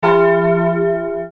glocke.mp3